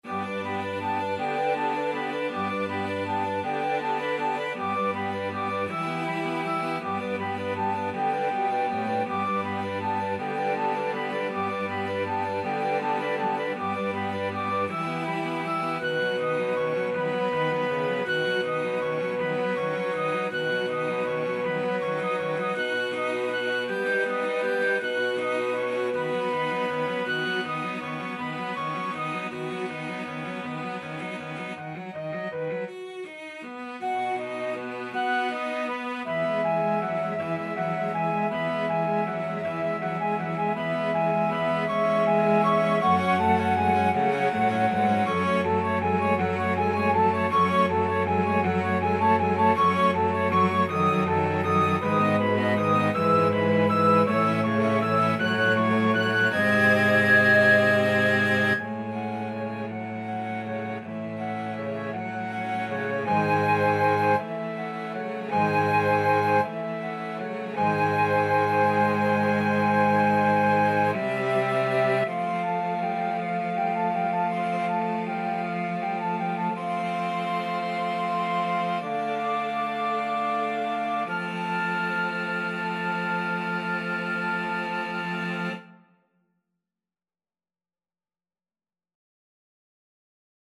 Free Sheet music for Flexible Mixed Ensemble - 5 Players
Flute
Cello
6/8 (View more 6/8 Music)
G major (Sounding Pitch) (View more G major Music for Flexible Mixed Ensemble - 5 Players )
Andante
Classical (View more Classical Flexible Mixed Ensemble - 5 Players Music)